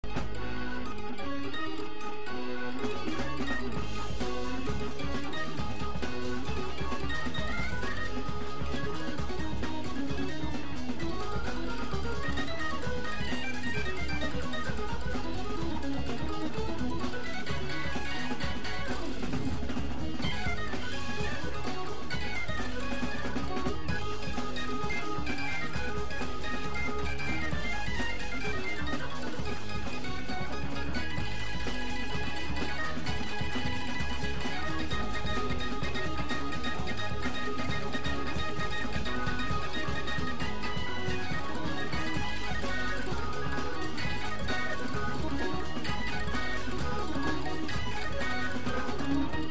ハモンドオルガン、モジュラームーグ、グランドピアノを使い、クラシック、ジャズの要素を取り入れた革新的なバンド。
3人で これでもかと繰り広げられる演奏は圧巻。